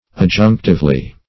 adjunctively - definition of adjunctively - synonyms, pronunciation, spelling from Free Dictionary
\Ad*junc"tive*ly\